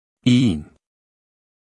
Phoneme_(Umshk)_(Iin)_(Male).mp3